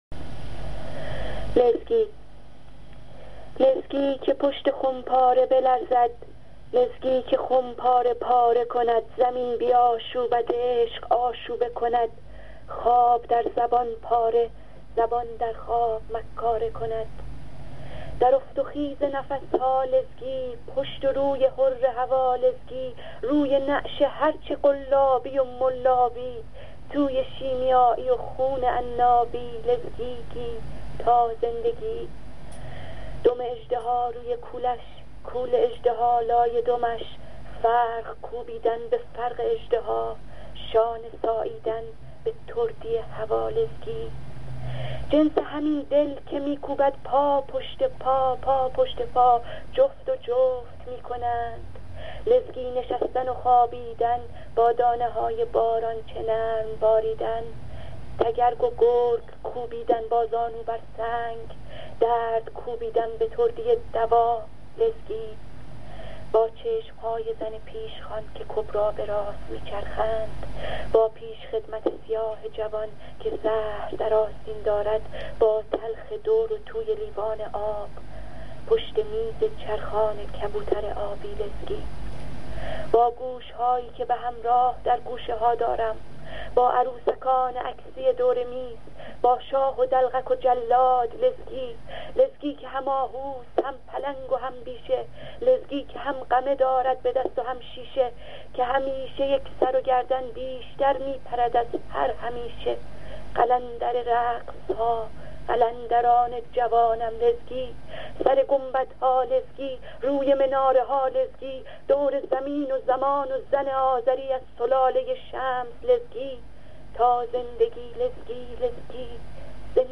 برای شنیدن این شعر با صدای شاعراین جا کلیک کنید